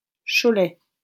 -Cholet.wav Plik audio z wymową.